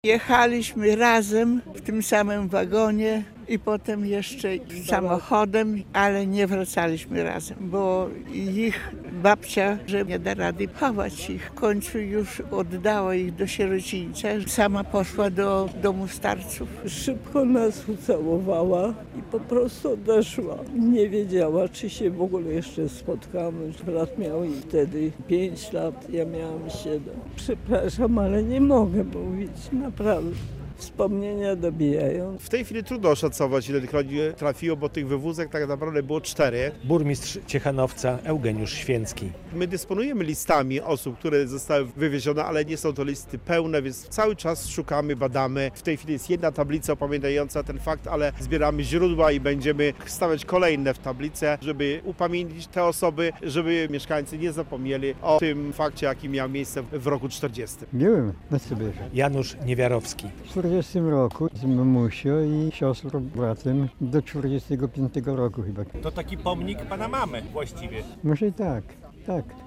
Odsłonięcie pomnika Matki Sybiraczki w Ciechanowcu - relacja
Dopiero ponowne nawiązanie kontaktów z Pińczowem okazało się sukcesem także dla tego pomysłu - mówi burmistrz Ciechanowca Eugeniusz Święcki.